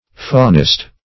Search Result for " faunist" : The Collaborative International Dictionary of English v.0.48: Faunist \Fau"nist\, n. One who describes the fauna of country; a naturalist.